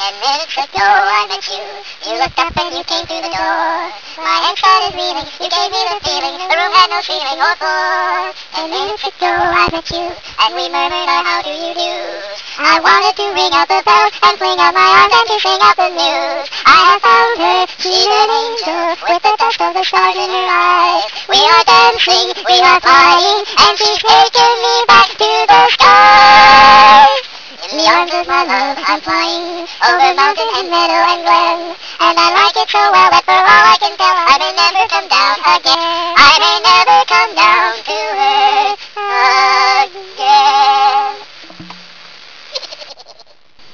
A "sped up" version
(double dubbed).